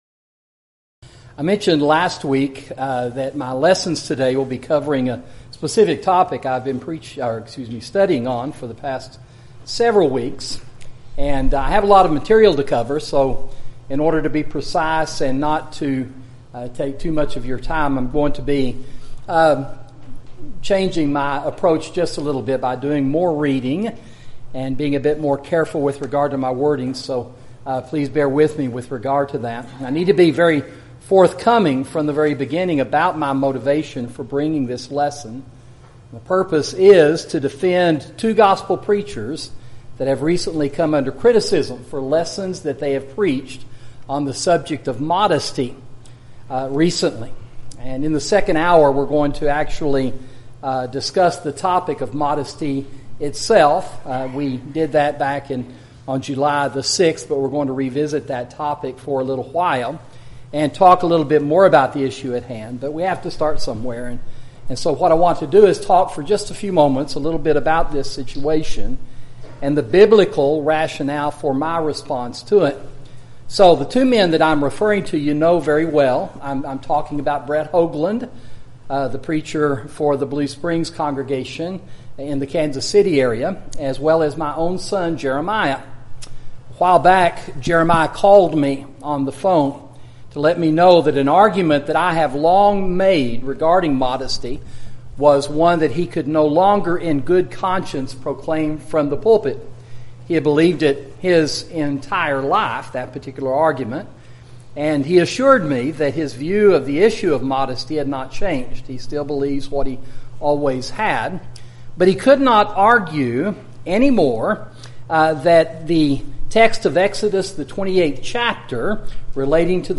Sermon: Truths About Preaching